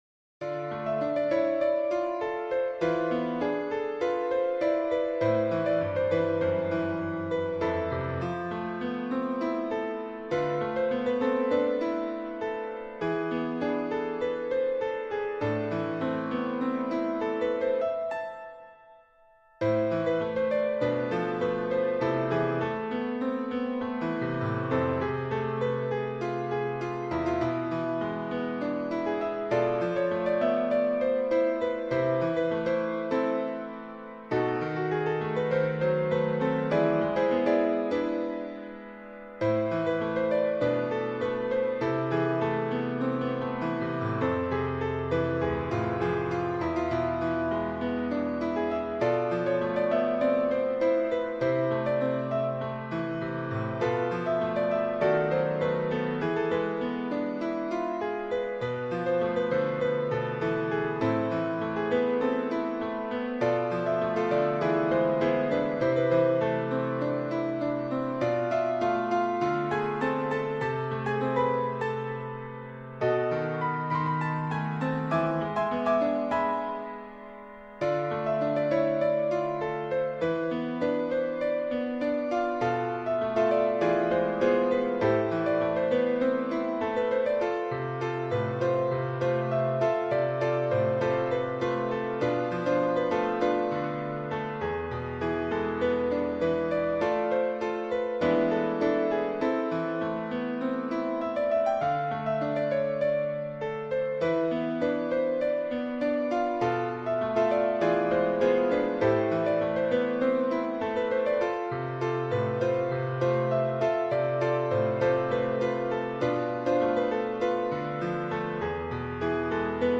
Mahnının melodiyası çox axıcı və zərifdir.